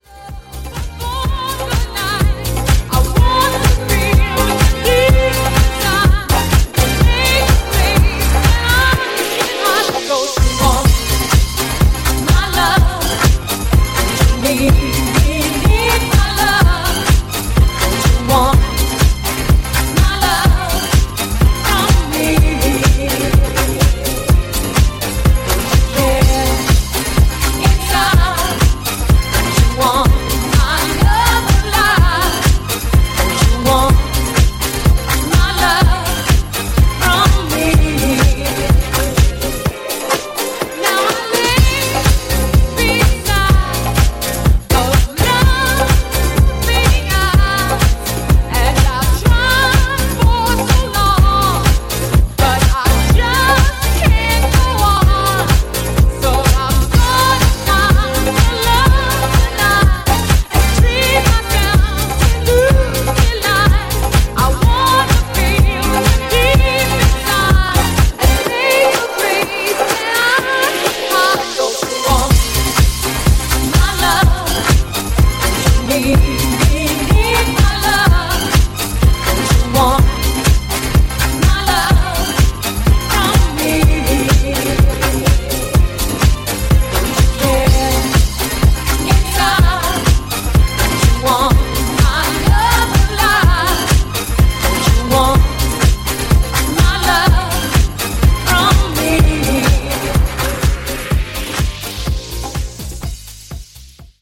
ジャンル(スタイル) DISCO / HOUSE